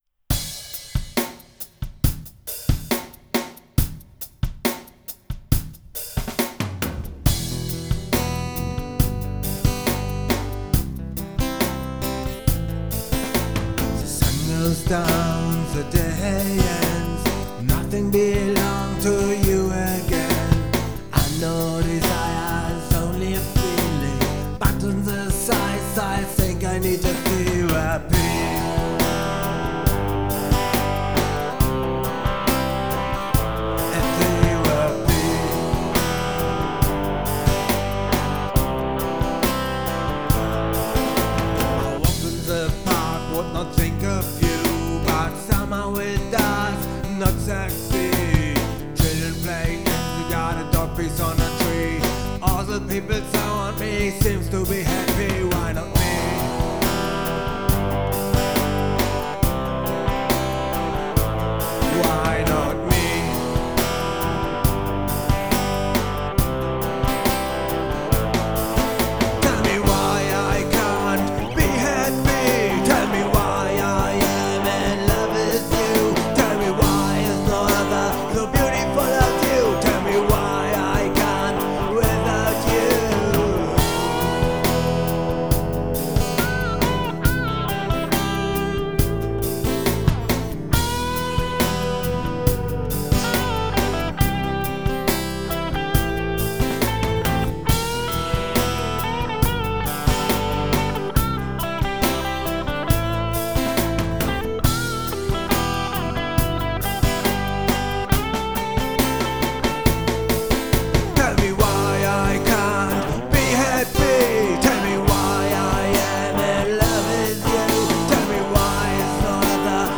Nur Demo-Versionen!